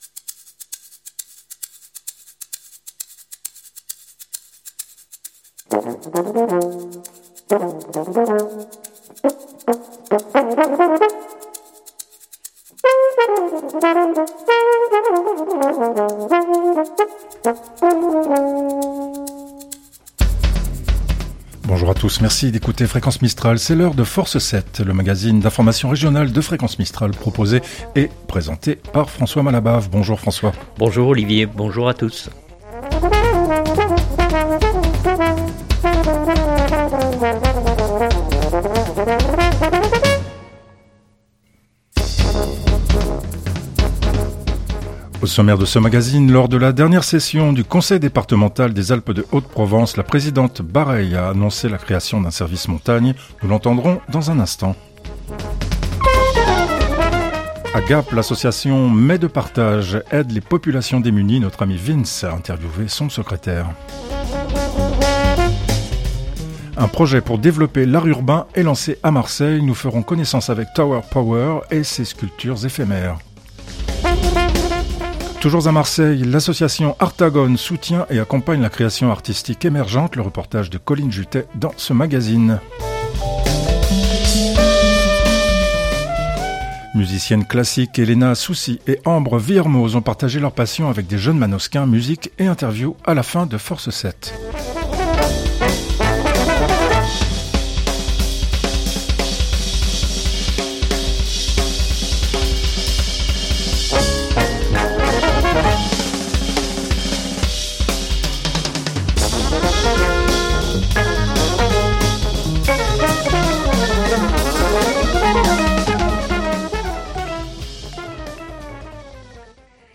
Musique et interview à la fin de Force 7.